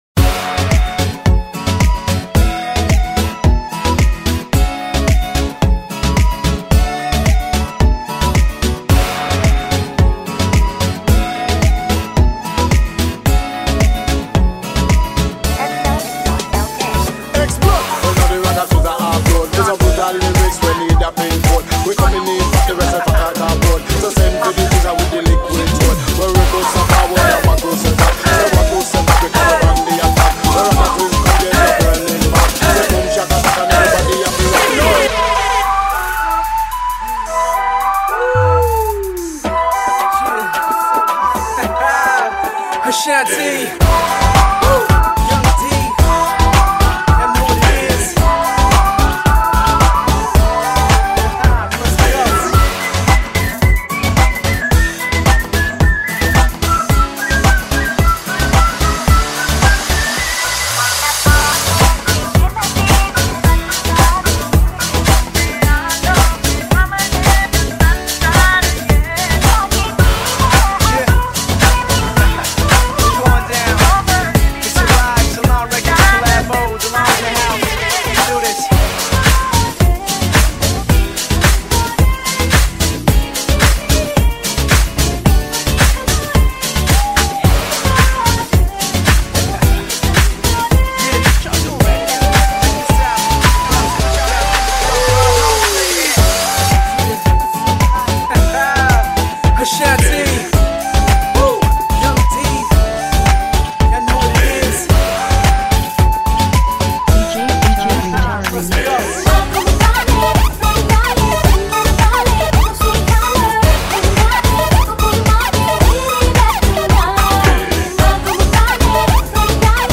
High quality Sri Lankan remix MP3 (7.2).